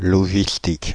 Ääntäminen
France (Paris): IPA: /lɔ.ʒi.stik/